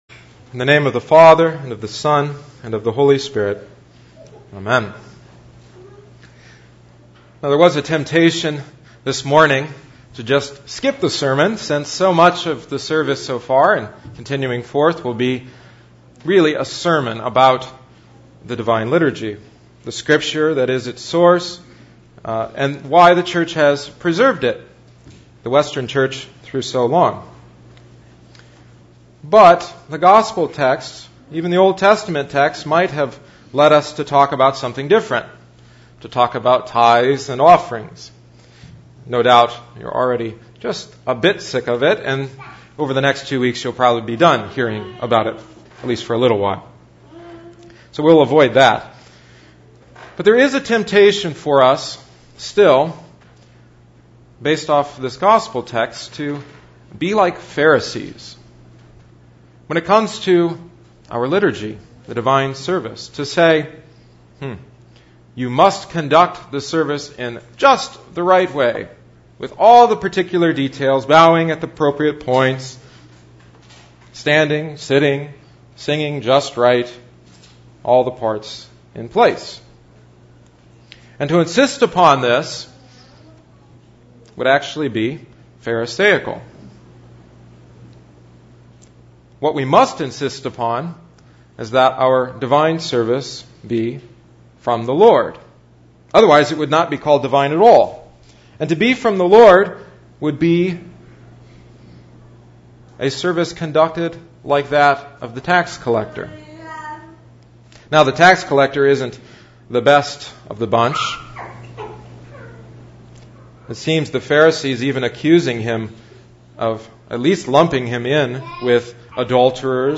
Today’s sermon is audio only, reflecting on the Pharisee and the Tax Collector in light of the Divine Liturgy: Sermon 2011-09-04